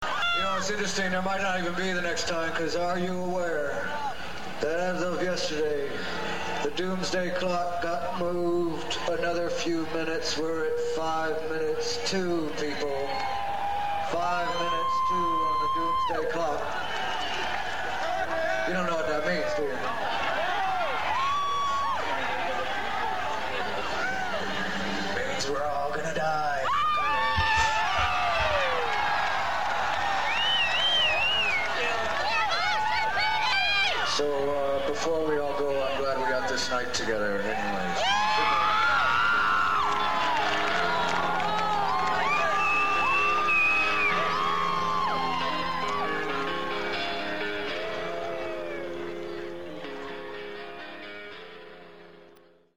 08/22/98 - Molson Park: Barrie, Ontario [125m]
mp3 soundbyte of doomsday speech (844k)   'Do the Evolution' features the new video on the backdrop.